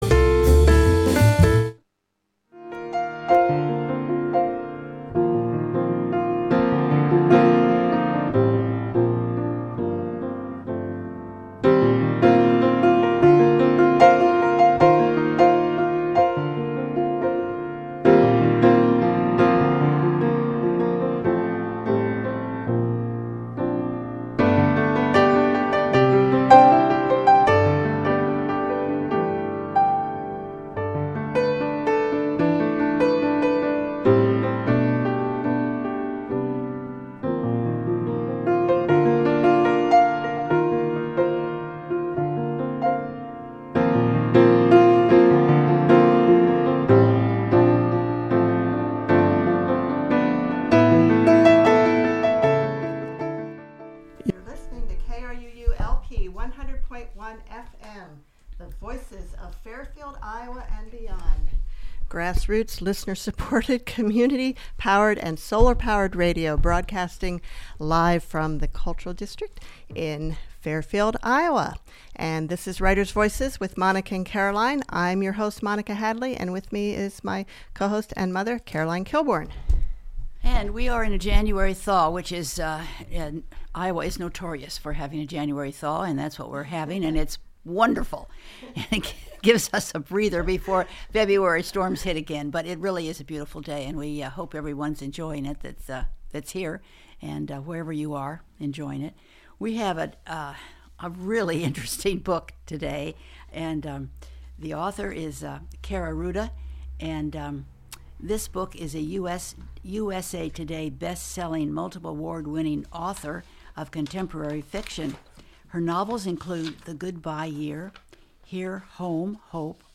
” A conversation about writing, publishing and her personal reflections on living the writer’s life.